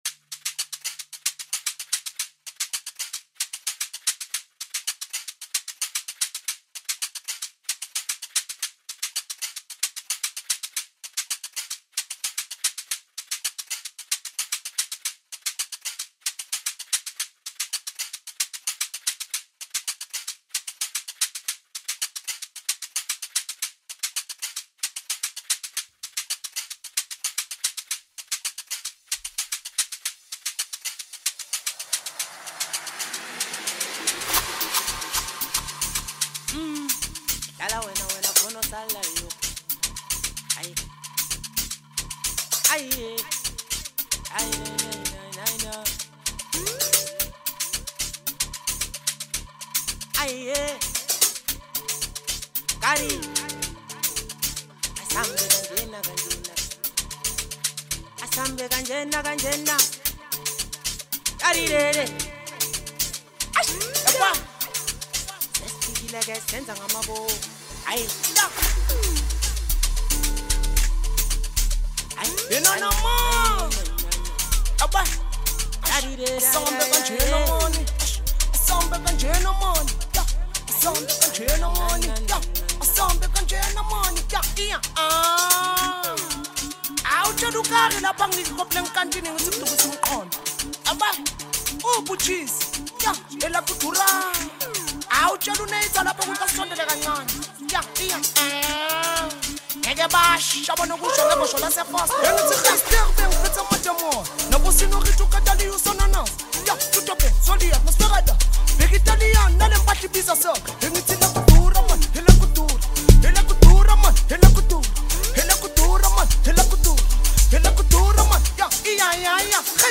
electrifying Amapiano anthem